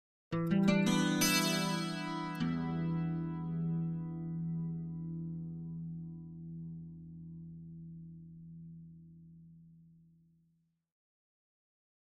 Acoustic Guitar - Arpeggio 2 - E Minor Chorus